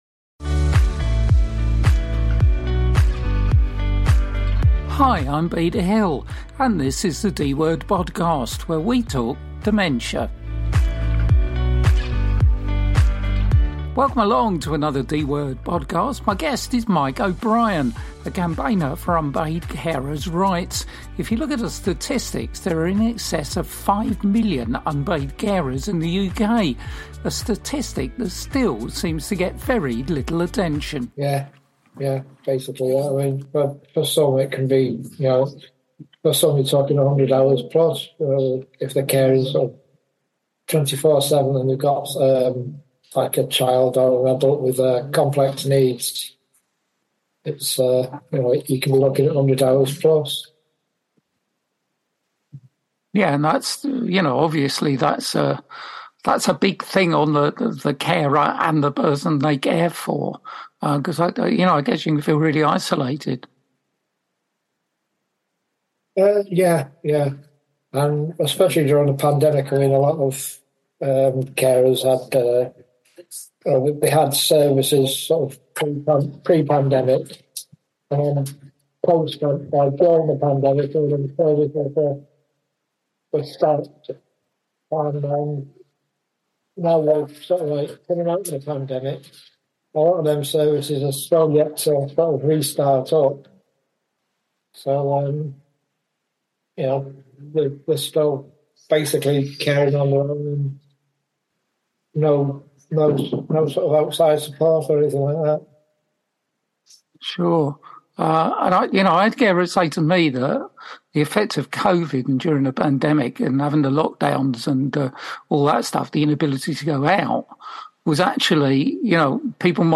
The ‘D’ Word is the UK’s only dementia-focused radio show.